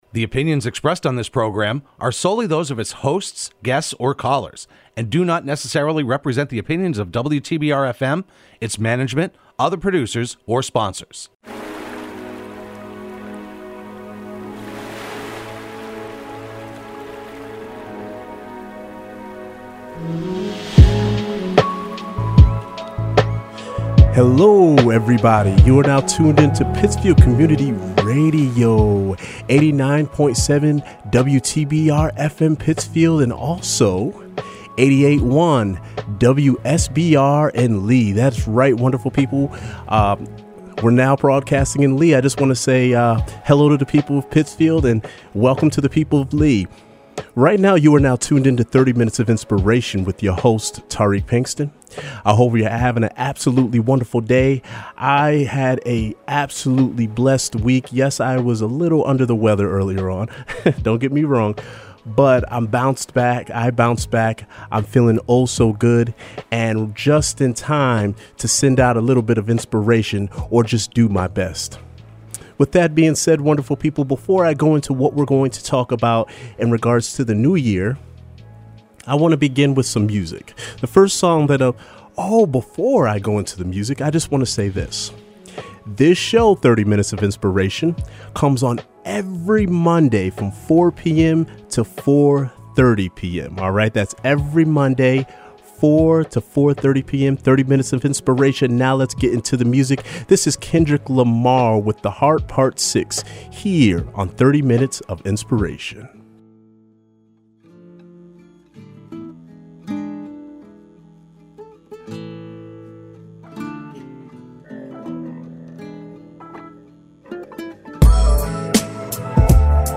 broadcast live every Monday afternoon at 4pm.